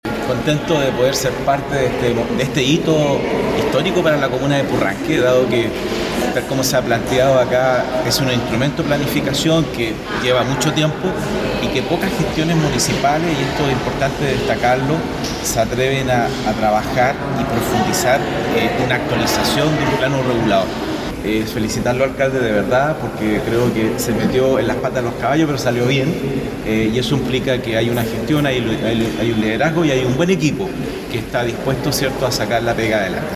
A su vez, el Consejero Regional  por la Provincia de Osorno, José Luis Muñoz, puntualizó en la importancia de este instrumento destacando la labor del equipo municipal y asegurando que al momento de entrar en vigencia será un hecho histórico para la región.